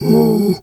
bear_pain_whimper_06.wav